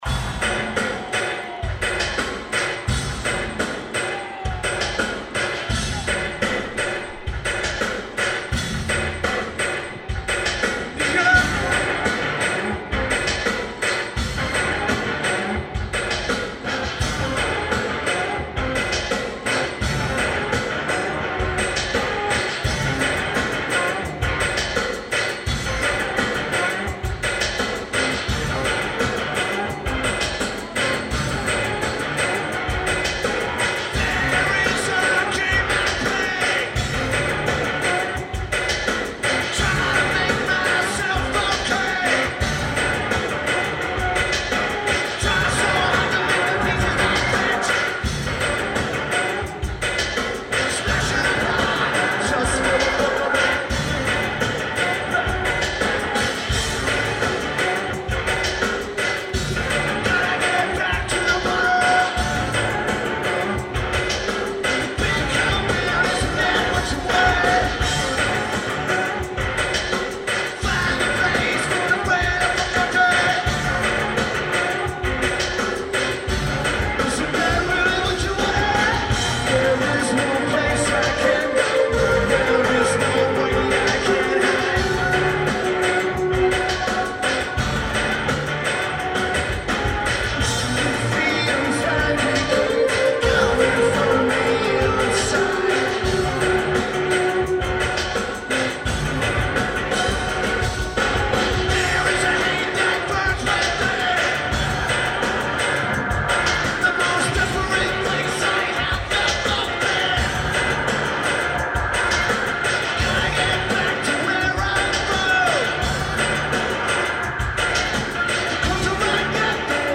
CSU Convocation Center